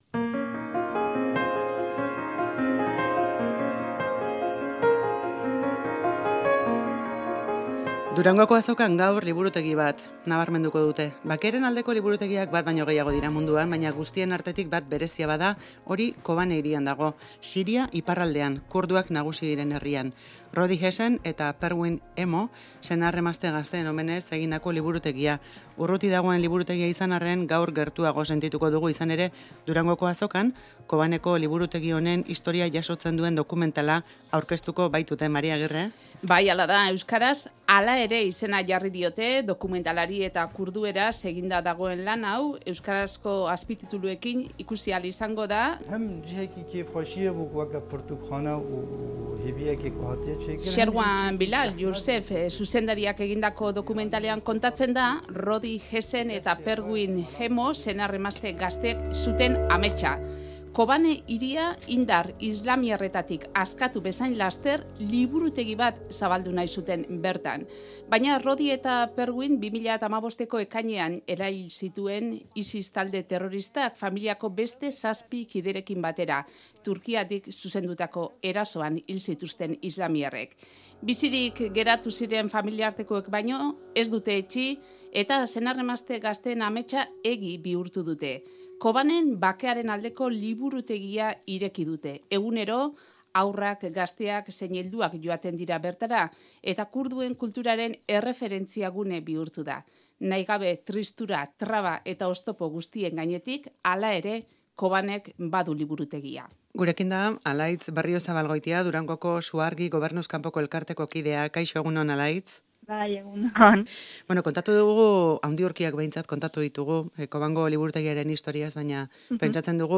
Audioa: Durangora etorri dira Rojava eskualdetik, Siria iparraldetik, 3 emakume kurdu.